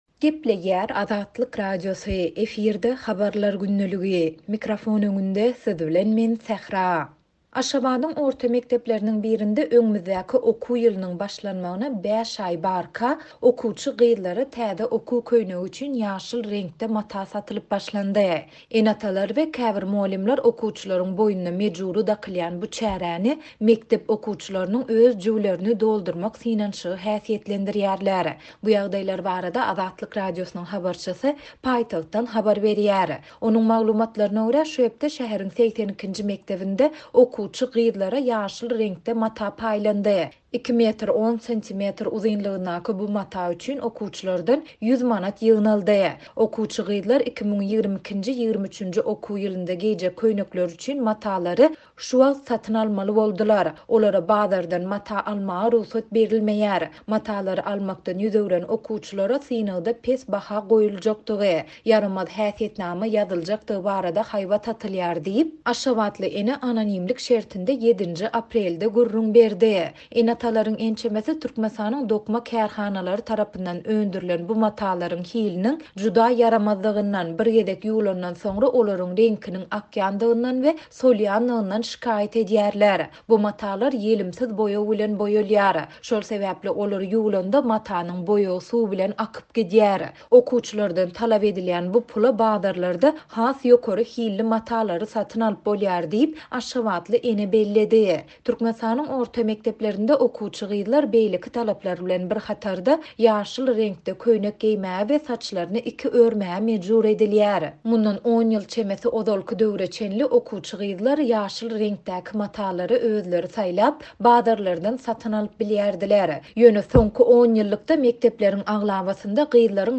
Bu ýagdaýlar barada Azatlyk Radiosynyň habarçysy paýtagtdan habar berýär.